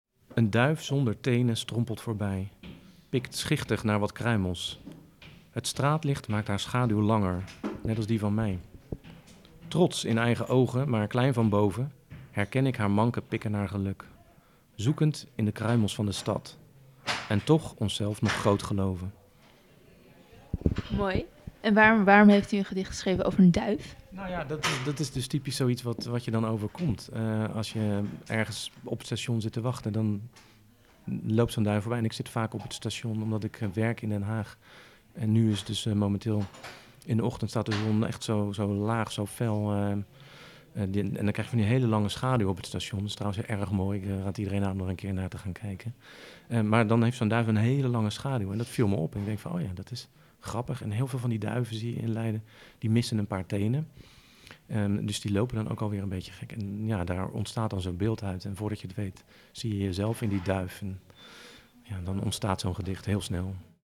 draagt één van zijn gedichten voor.